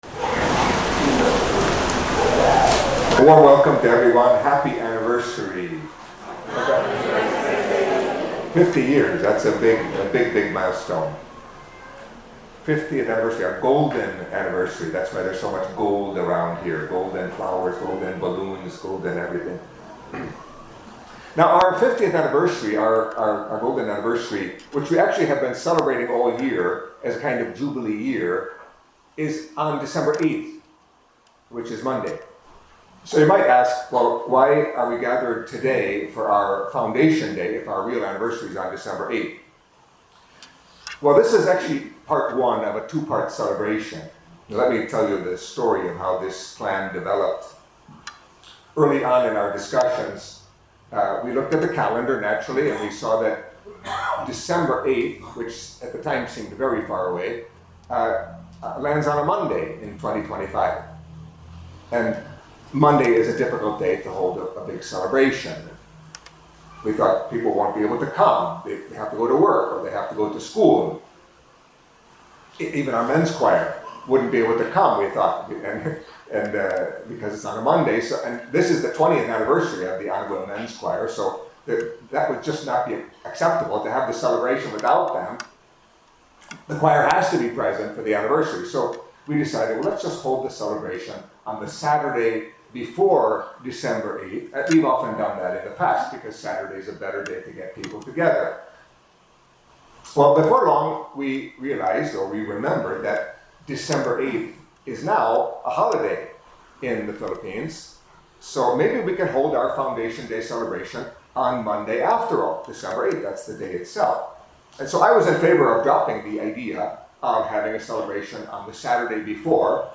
Catholic Mass homily for Saturday of the First Week of Advent